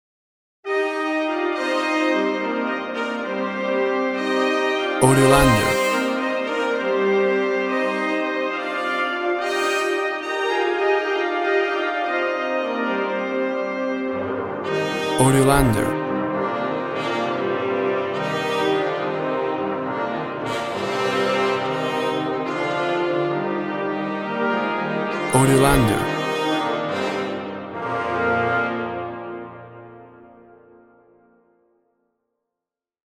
A traditional brass band version
Tempo (BPM) 175